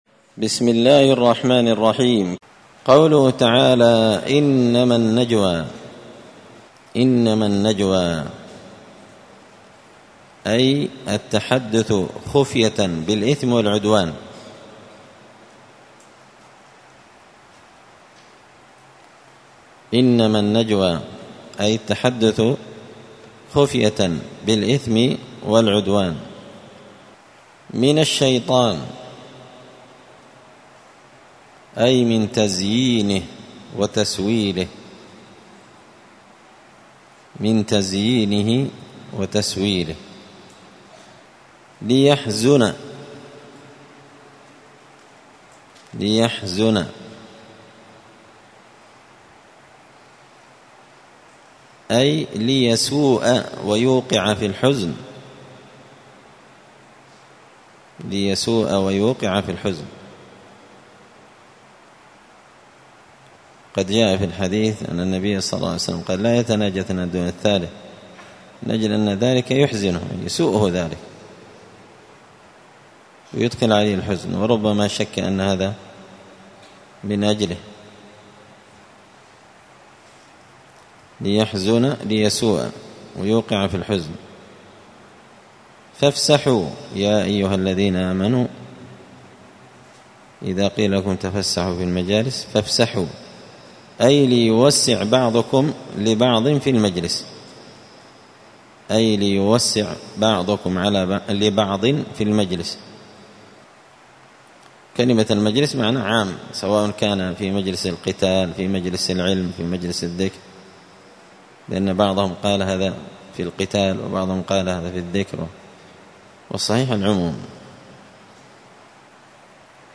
*(جزء المجادلة سورة المجادلة الدرس 123)*